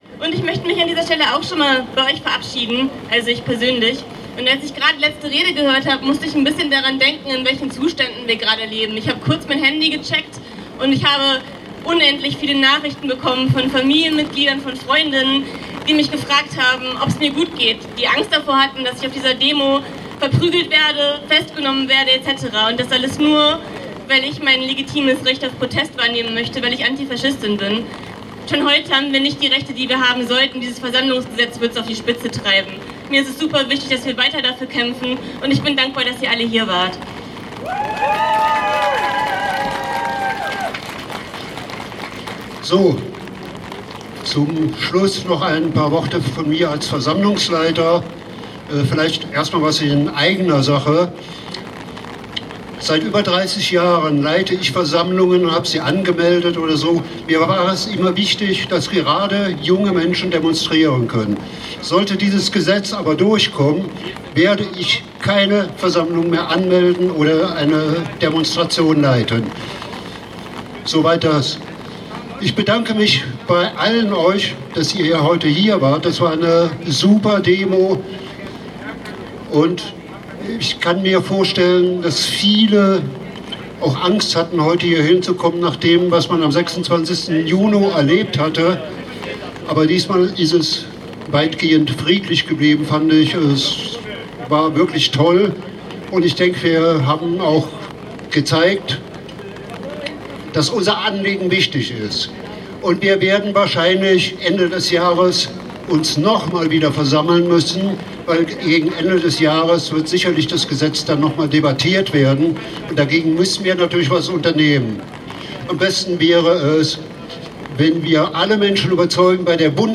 Demonstration: „Versammlungsgesetz NRW stoppen! Grundrechte erhalten! Jetzt erst recht!“ (Audio 16/17)